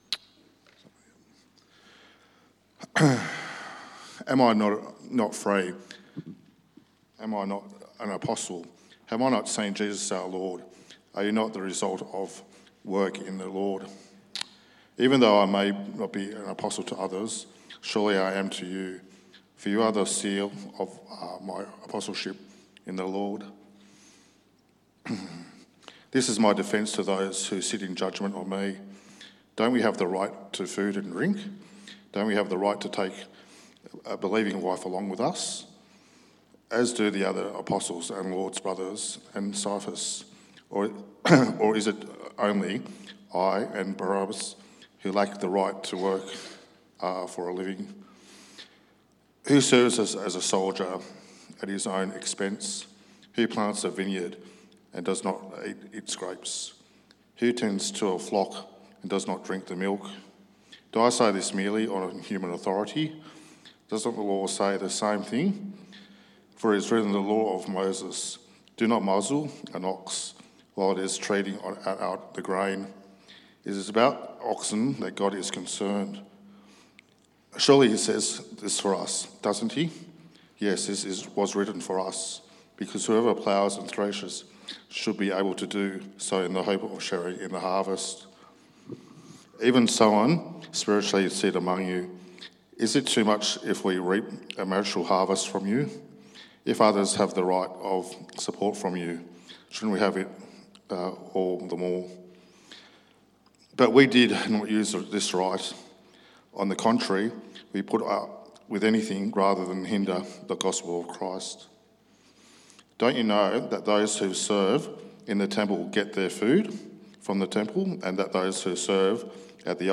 Preacher
1 Corinthians 9:1-18 Service Type: 6PM Should we fight for our rights